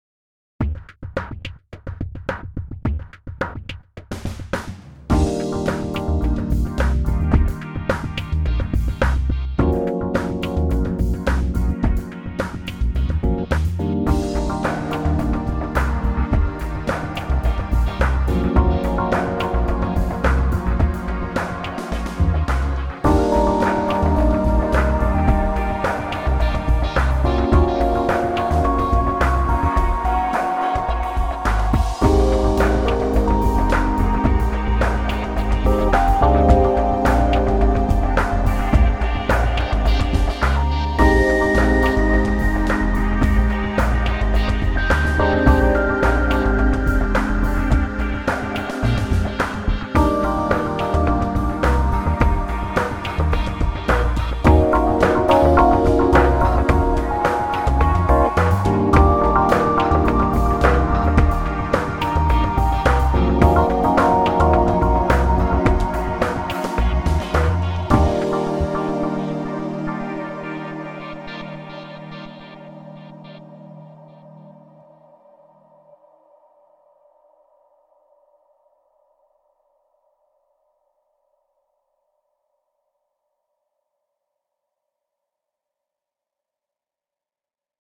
Groovin' wide stereo mixed track